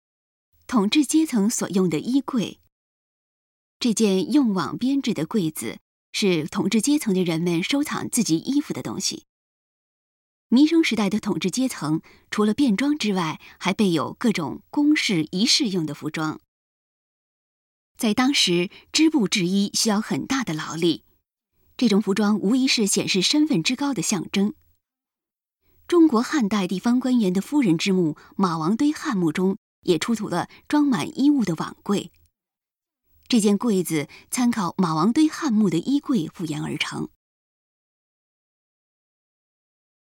语音导览 前一页 下一页 返回手机导游首页 (C)YOSHINOGARI HISTORICAL PARK